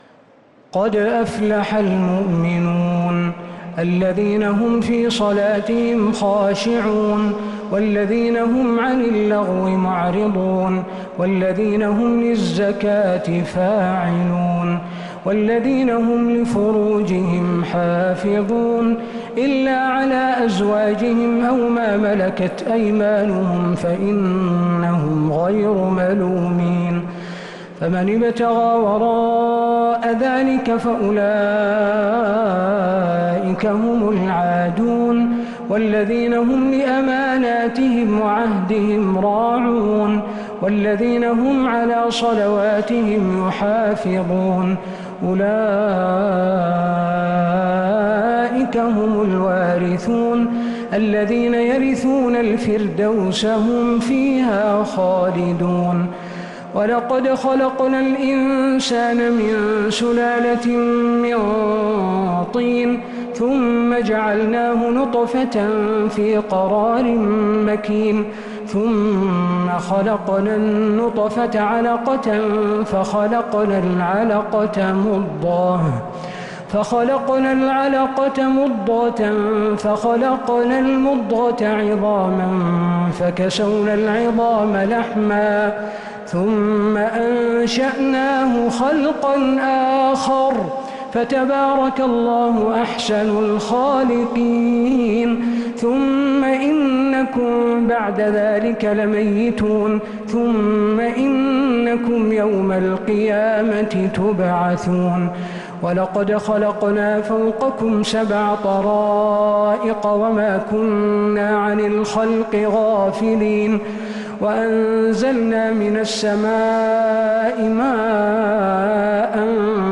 سورة المؤمنون كاملة صلاة الخسوف 15 ربيع الأول 1447هـ.